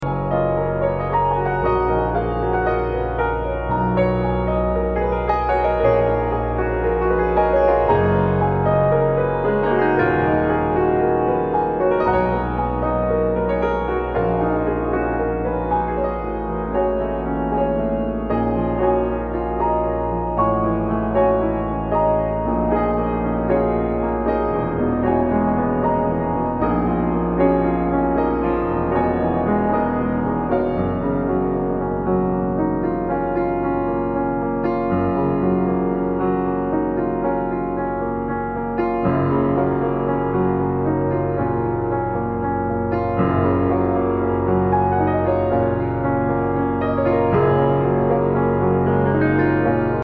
Post Classical >